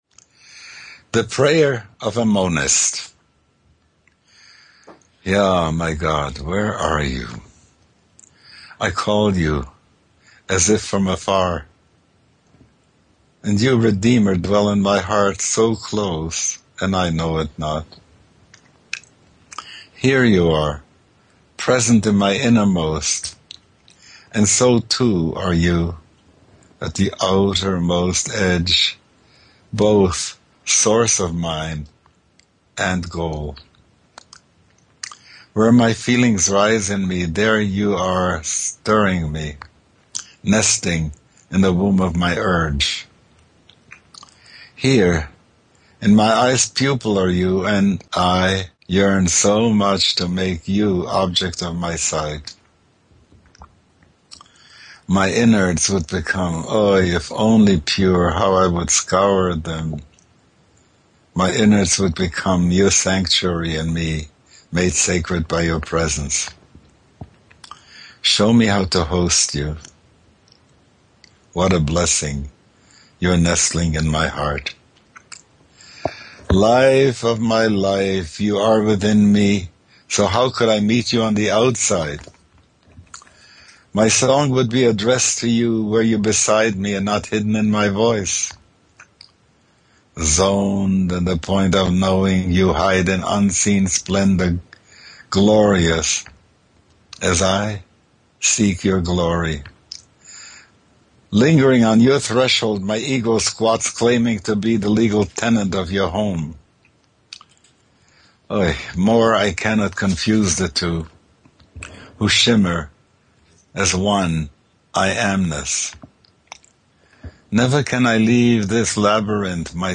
These recordings were not done in the studio under perfect conditions with sophisticated microphones and complicated mixers.  They were made over Skype using a Skype recorder and edited using a simple audio editor.